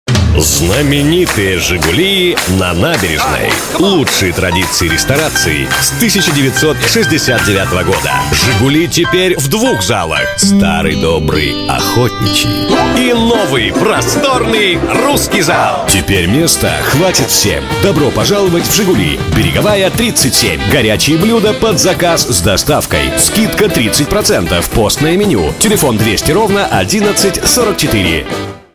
Professioneller russischer Sprecher für TV / Rundfunk / Industrie. Professionell voice over artist from Russia.
Sprechprobe: Industrie (Muttersprache):